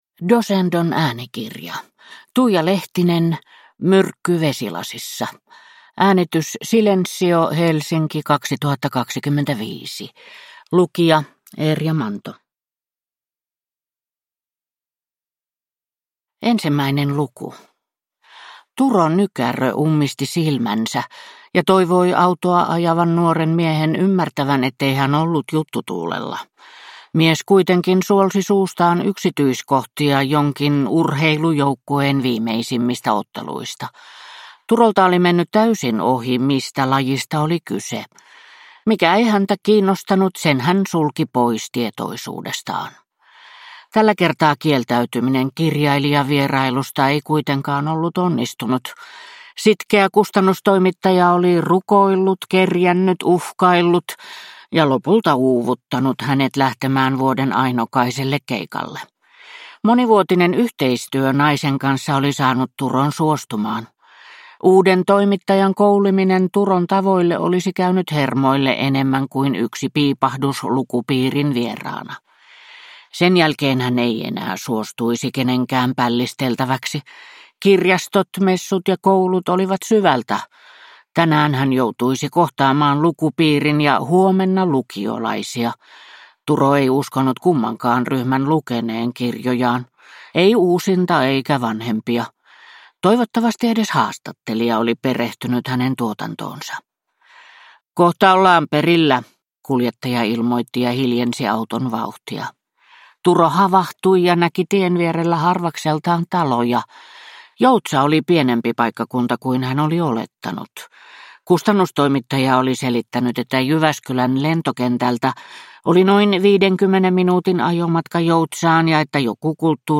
Myrkky vesilasissa (ljudbok) av Tuija Lehtinen